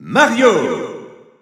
French Announcer announcing Mario.
Mario_French_Announcer_SSBU.wav